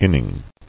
[in·ning]